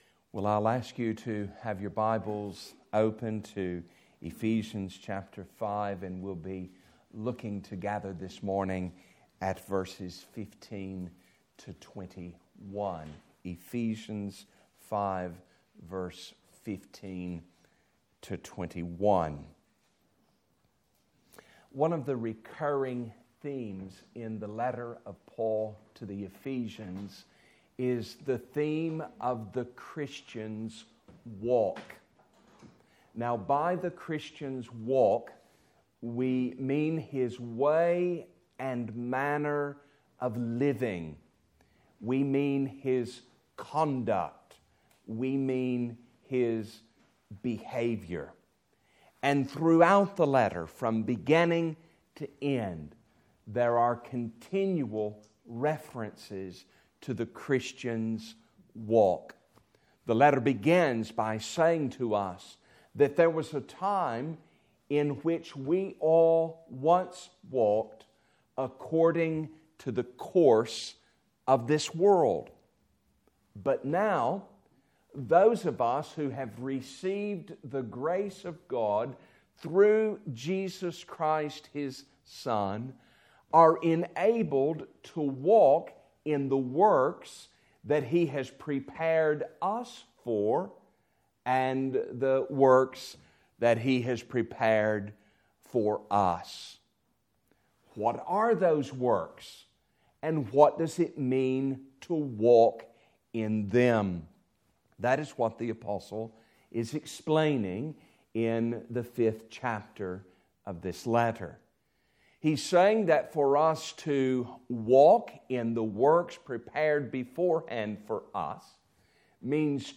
Ephesians Passage: Ephesians 5:15-21 Service Type: Sunday Morning « Sunday 26th February 2017